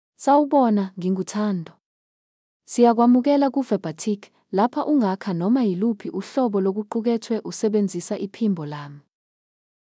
ThandoFemale Zulu AI voice
Thando is a female AI voice for Zulu (South Africa).
Voice sample
Female
Thando delivers clear pronunciation with authentic South Africa Zulu intonation, making your content sound professionally produced.